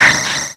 Cri d'Anorith dans Pokémon X et Y.